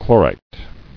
[chlo·rite]